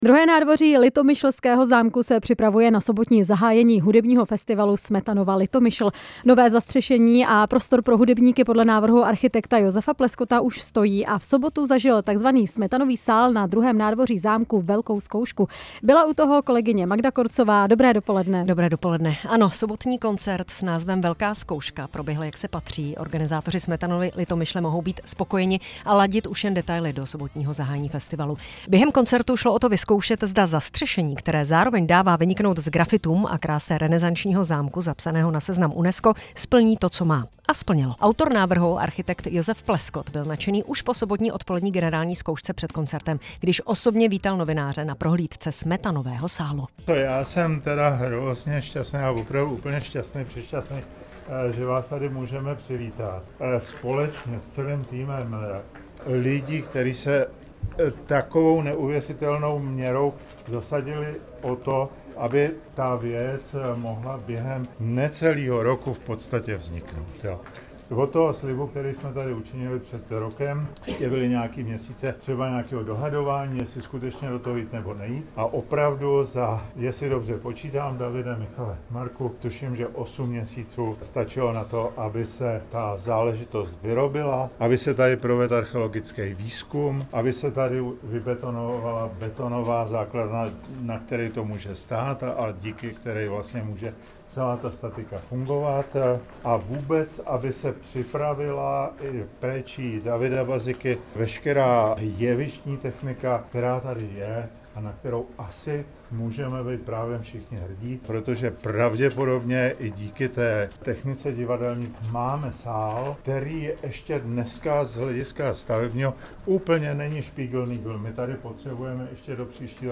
Reportáž z Litomyšle